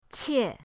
qie4.mp3